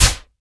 spikefire.wav